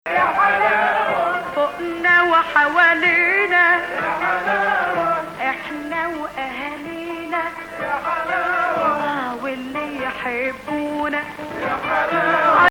Ajam 4
includes flat 6 above (= 9 relative Hijaz)